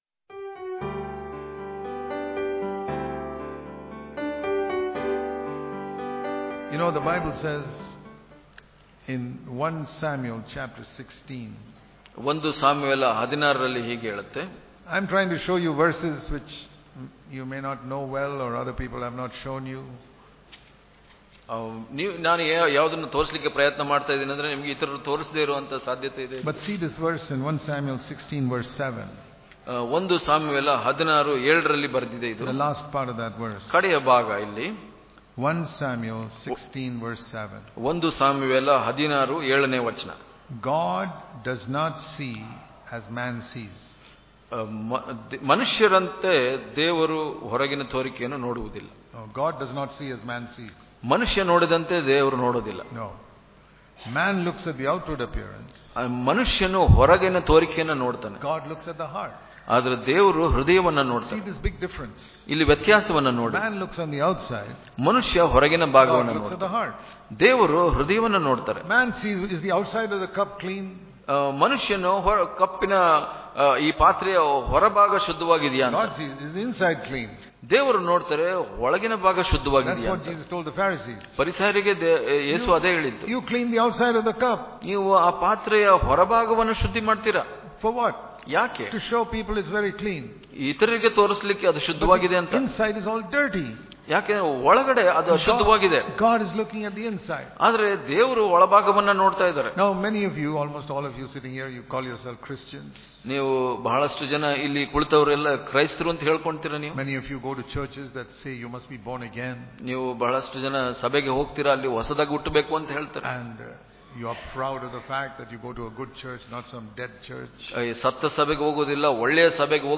March 23 | Kannada Daily Devotion | God Looks At The Heart, Not At The Outward Appearance Daily Devotions